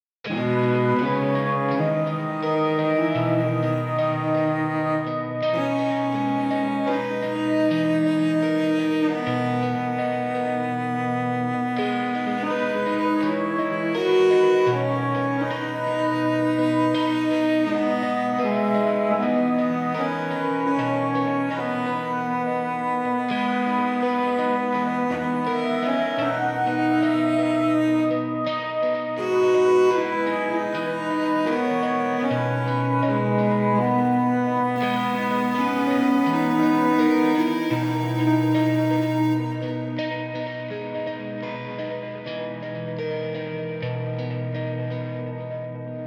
Feedback für meinen ersten demo Instrumental mit Ableton 12 lite
Dieses kurze Demo-Stück ist noch unpoliert und sehr roh, aber ich hoffe trotzdem auf ehrliches Feedback von euch.